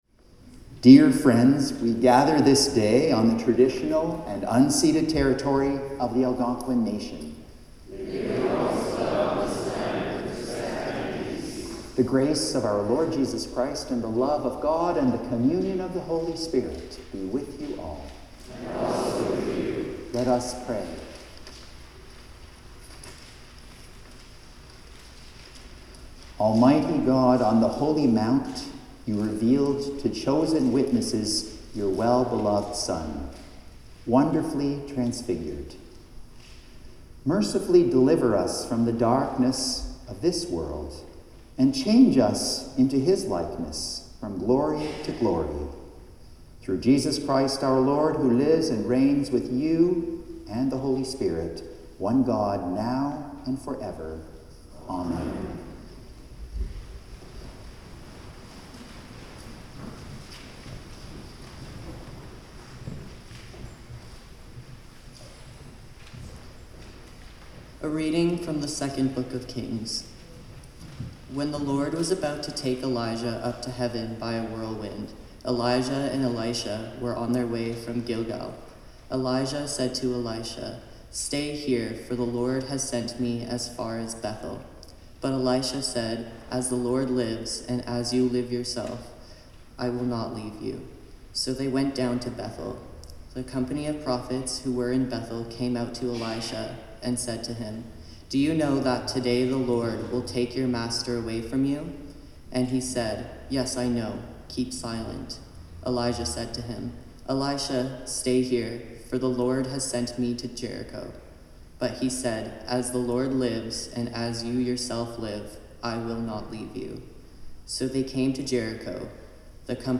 TRANSFIGURATION SUNDAY Greeting & Collect of the Day First Reading: 2 Kings 2:1-12 Hymn: Let There Be Light – Common Praise #572 (words below) Gospel: Mark 9:2-9 First Nations Translation Sermon